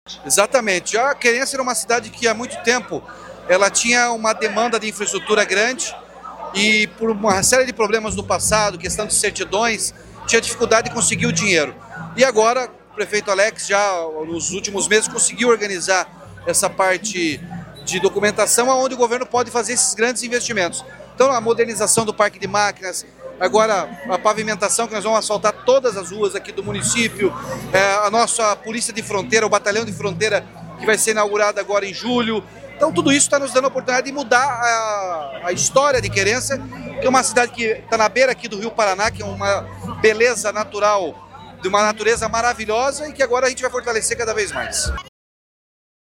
Sonora do governador Ratinho Junior sobre o anúncio de quase R$ 120 milhões em investimentos para Querência do Norte